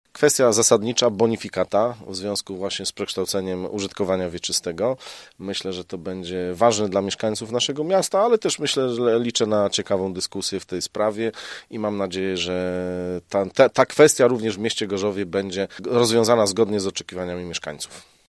Zdaniem Radosława Wróblewskiego, przewodniczącego klubu radnych Koalicja Obywatelska, obrady zdominuje temat bonifikaty